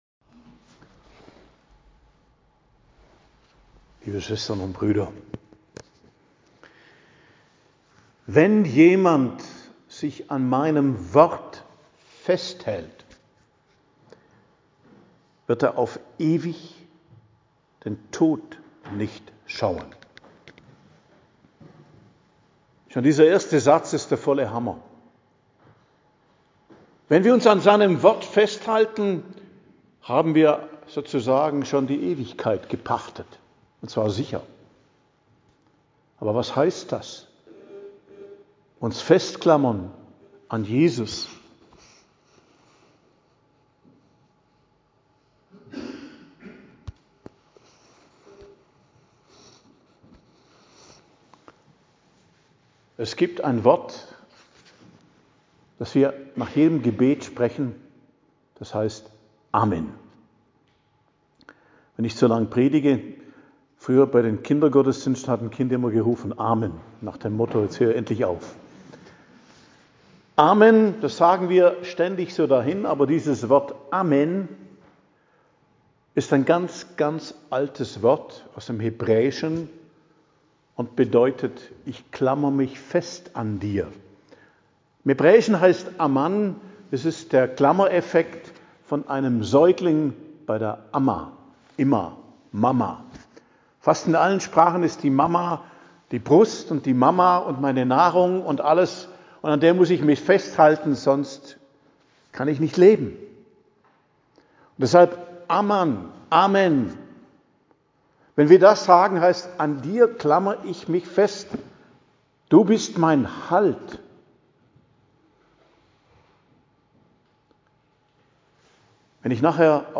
Predigt am Donnerstag der 5. Woche der Fastenzeit, 26.03.2026